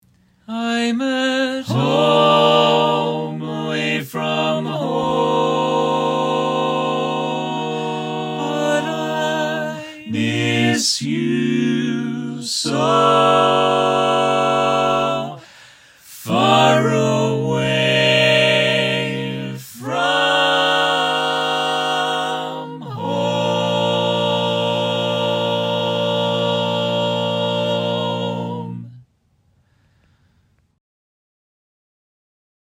Key written in: B♭ Major
How many parts: 4
Type: Barbershop
All Parts mix:
Learning tracks sung by